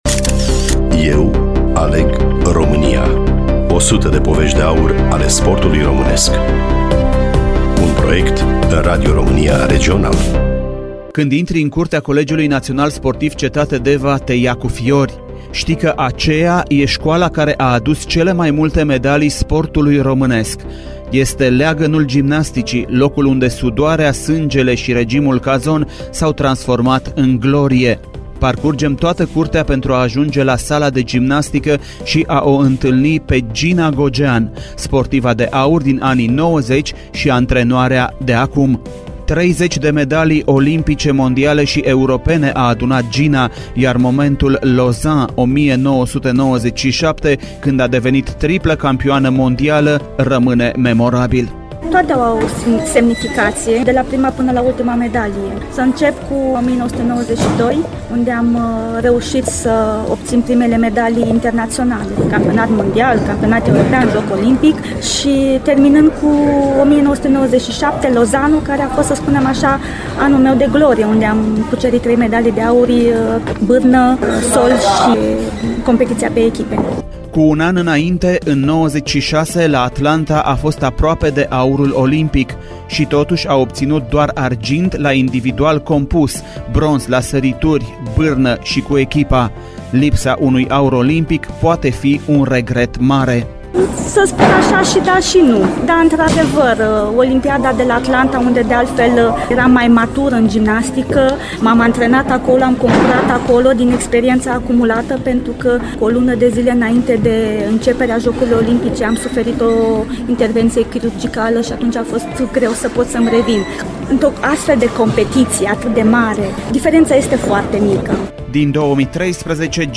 Studiul Radio Romania Timisoara
GINA-GOGEAN-varianta-RADIO.mp3